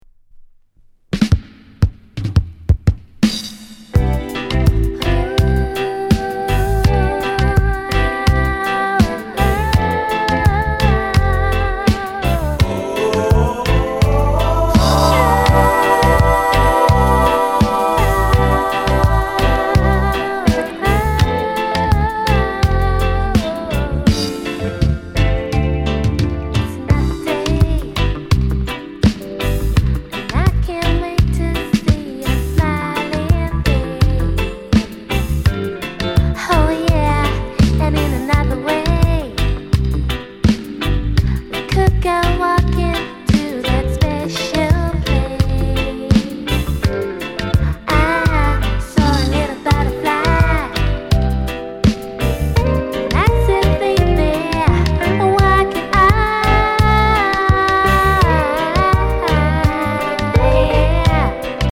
SWEET LOVERS ROCK